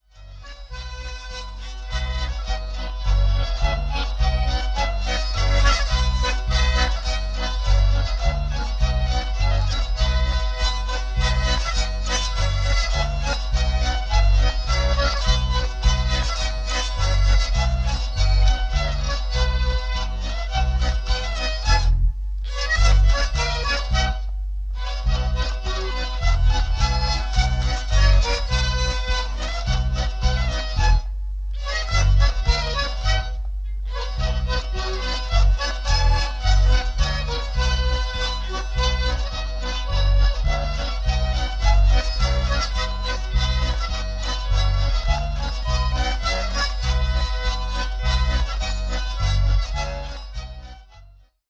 Die eingestellten Hörbeispiele sind also immer live eingespielt - entweder in normale Kassettengeräte - oder bestenfalls in Mischpulte mit Effekten. Die so entstandene mindere Klangqualität bitte ich zu entschuldigen
Zwiefacher